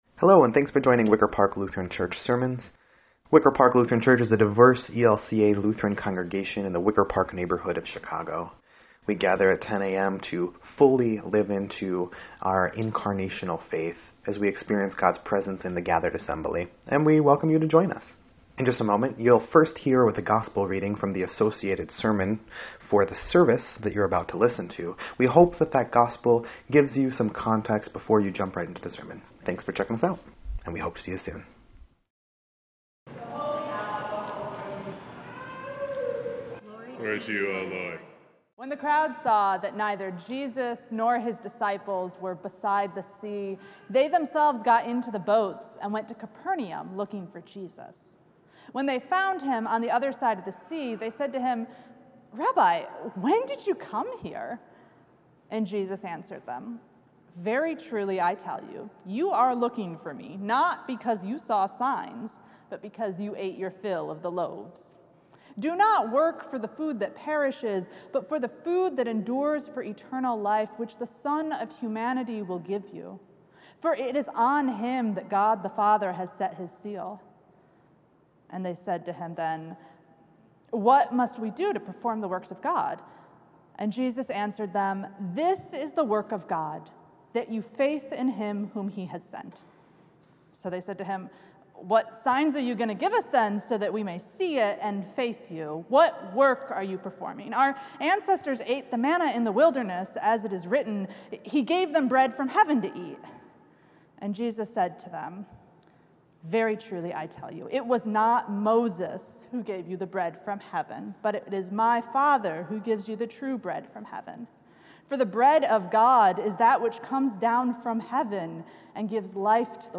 8.4.24-Sermon_EDIT.mp3